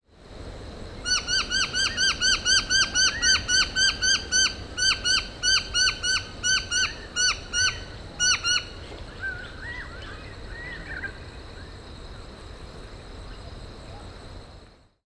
Noisy Miner
Manorina melanocephala
A loud ‘pwee pwee pwee’ and a piping ‘pee pee pee’ when alarmed.
The name is well suited as the common calls are uttered repeatedly by the members of the colony.
Noisy-Miner-Manorina-melanocephala.mp3